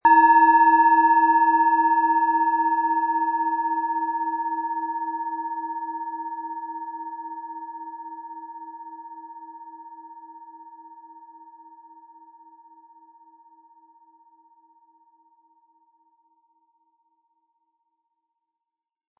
Tibetische Herz-Schulter- und Kopf-Klangschale, Ø 12,3 cm, 260-320 Gramm, mit Klöppel
HerstellungIn Handarbeit getrieben
MaterialBronze